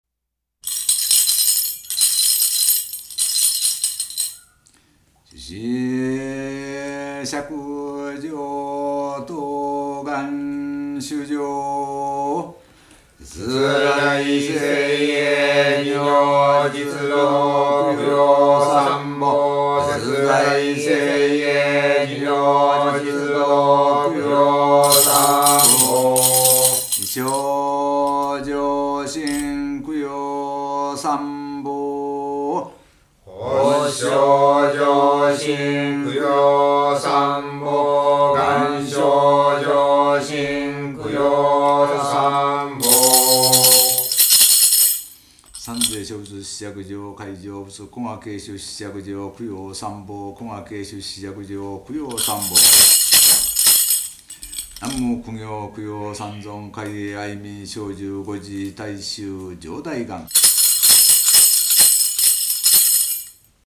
お経と声明
日常勤行式（朝課）